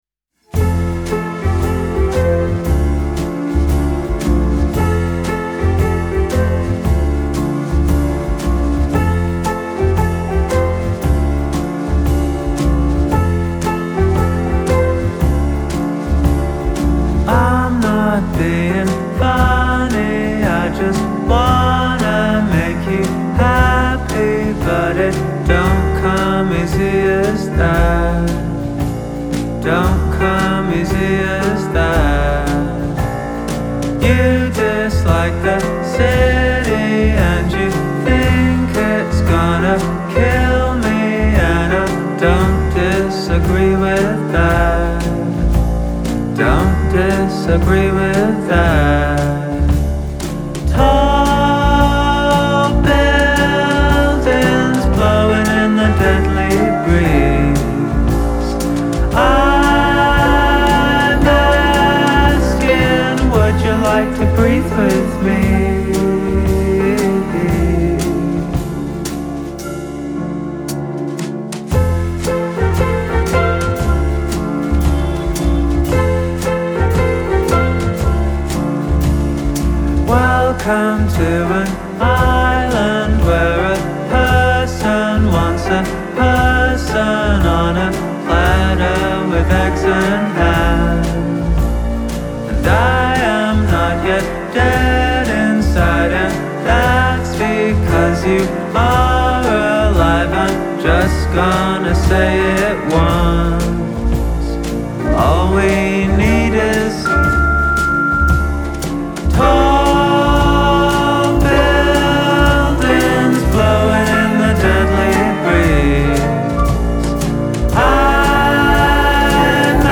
Genre : Alternative, Rock